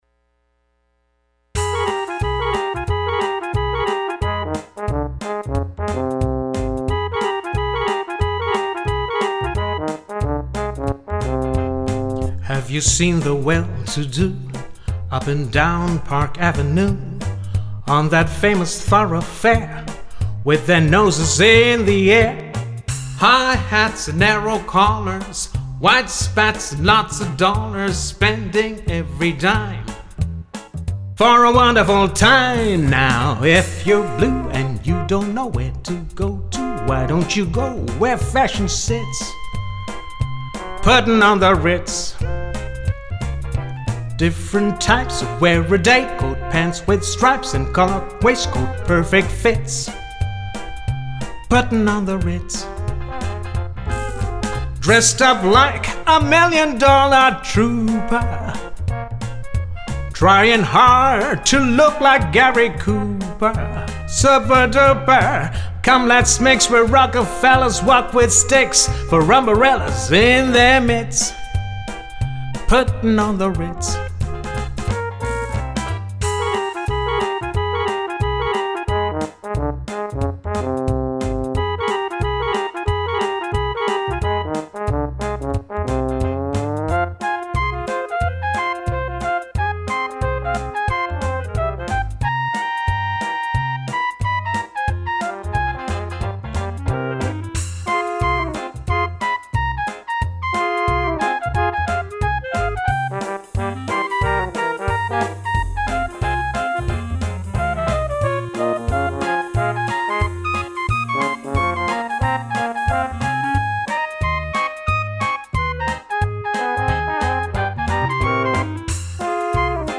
Swingin’ Jazz Standards / Dixieland / Latin Jazz